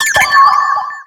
Cri de Phione dans Pokémon X et Y.